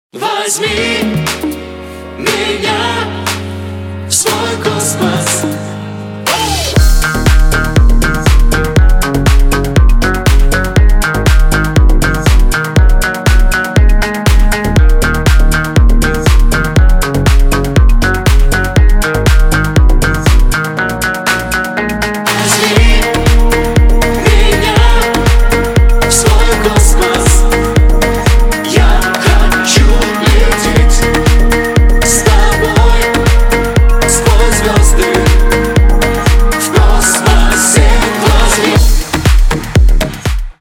dance
club
house